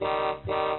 honkhonk-med.mp3